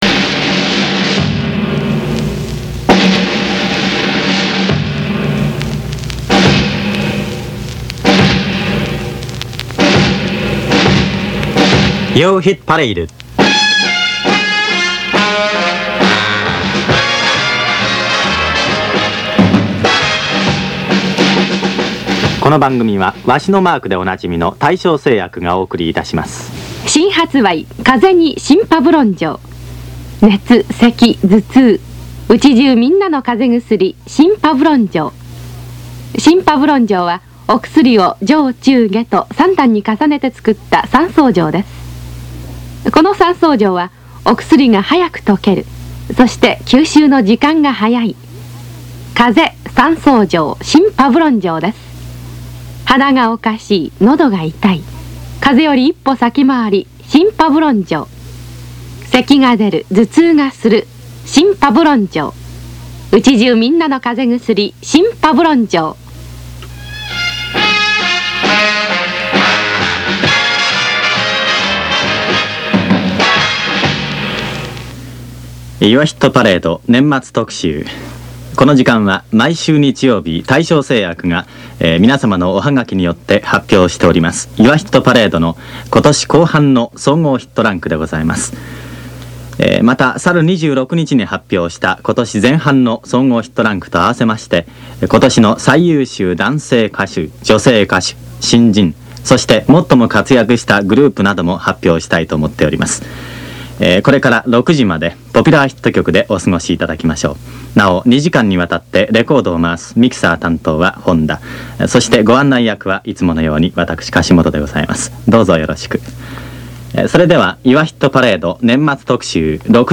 スコッチのテープです。
秒速９・５㎝のスピードでオープンテープに録音しています。
ＳＯＵＮＤの魅力、№１ユアヒットパレードから同録、曲はサティスファクション/ローリングストーンズ
ドラムの音！
１９６５年の放送です。
それにしても音がいいのはフルトラック録音のせいでしょう。